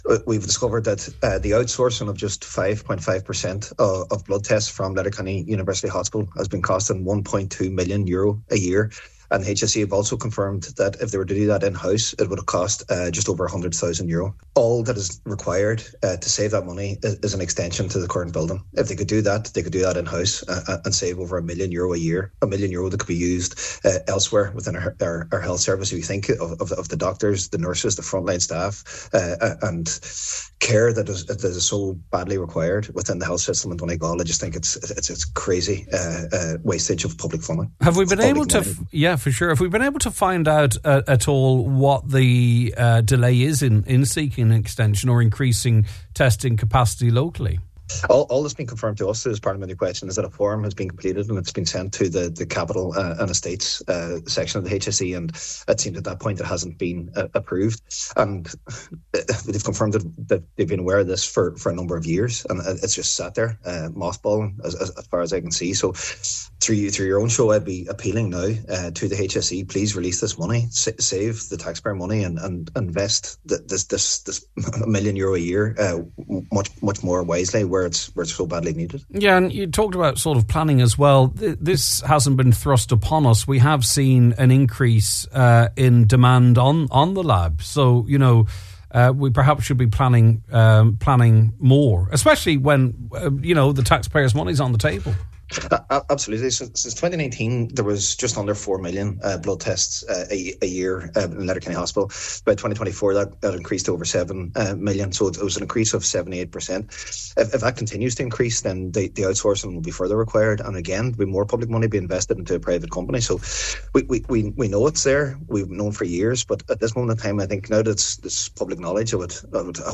on this morning’s Nine til Noon Show